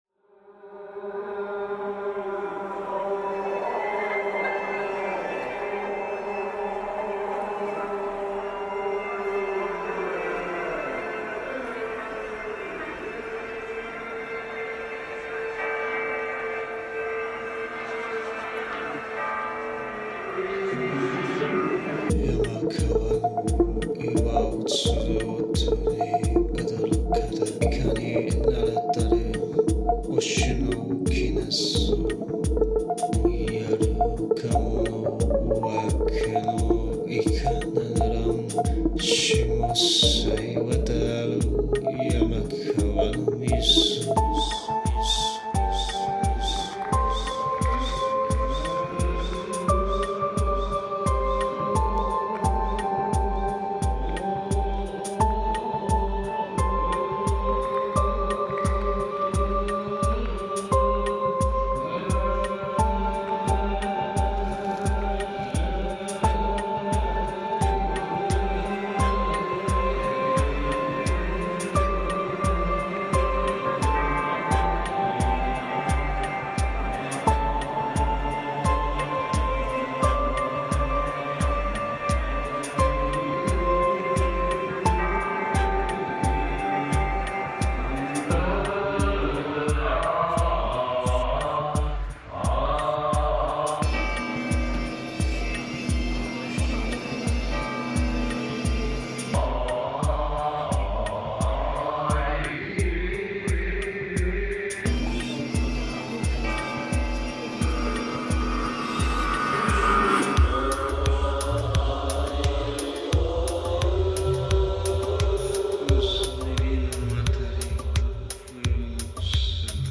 Praza das Praterías, Santiago de Compostela reimagined